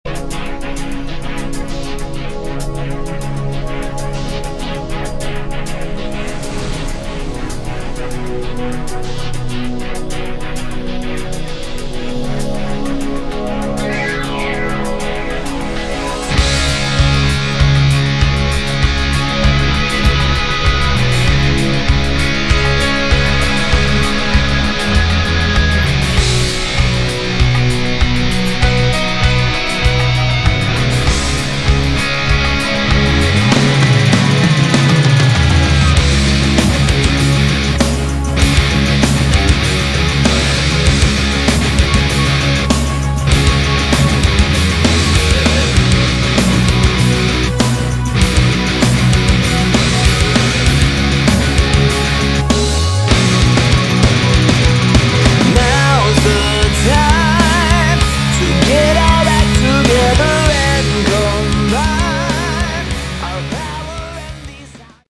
Category: Melodic Rock / AOR
guitar, bass, synthesizer, backing vocals
drums, percussion
keyboards
piano
flute, backing vocals